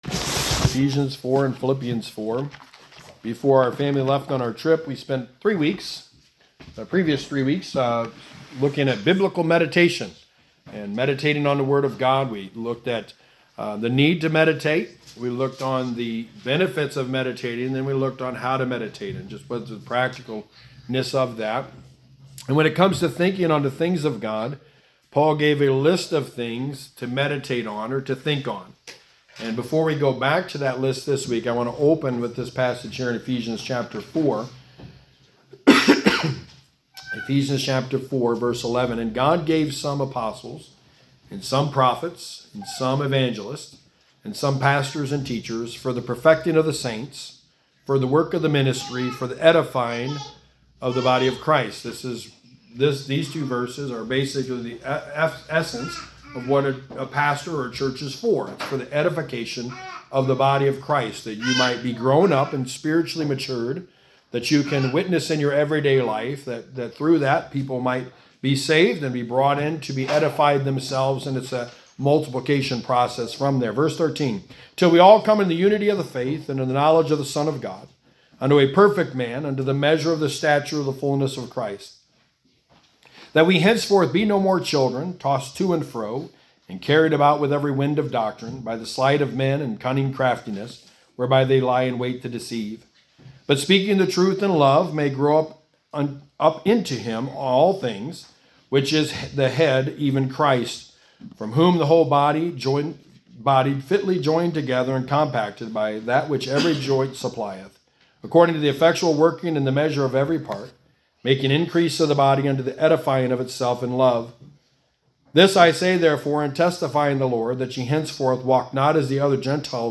Passage: Philippians 4:8 Service Type: Sunday Morning